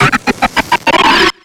Cri d'Hippopotas dans Pokémon X et Y.